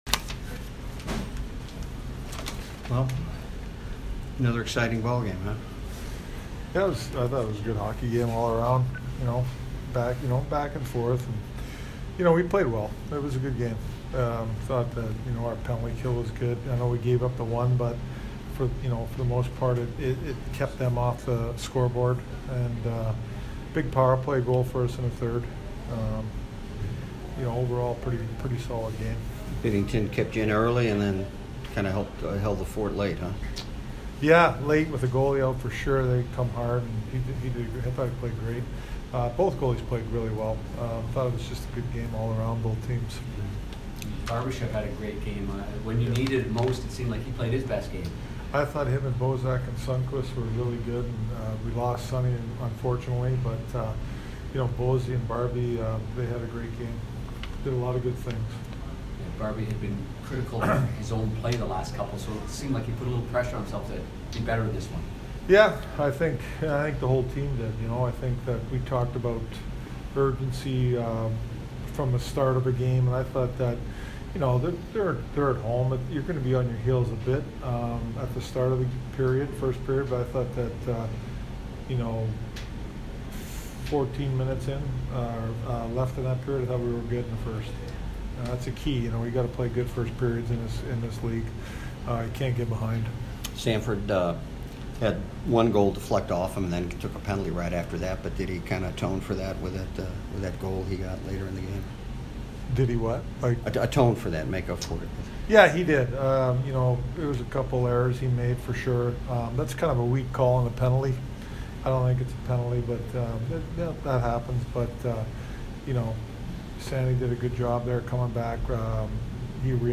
Berube post-game 11/27